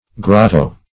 grotto \grot"to\ (gr[o^]t"t[-o]), n.; pl. Grottoes